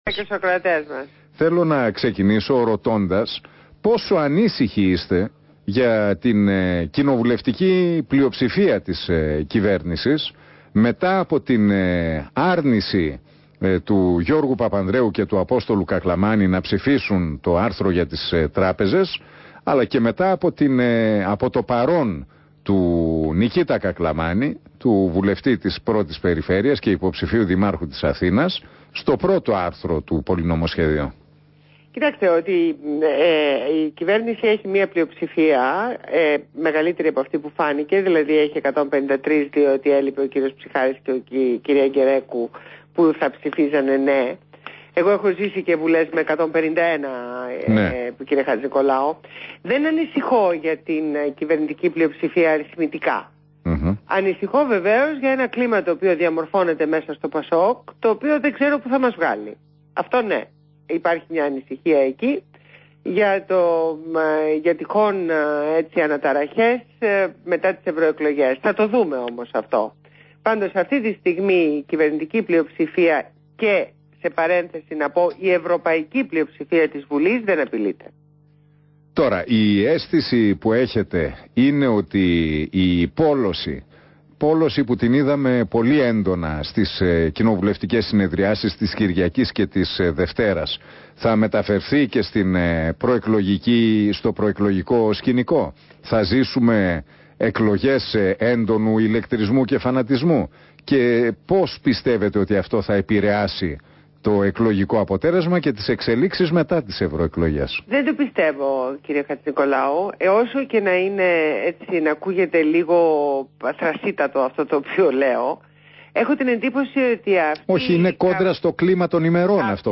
Συνέντευξη στο ραδιόφωνο του REAL fm
Ακούστε τη συνέντευξη στον Νίκο Χατζηνικολάου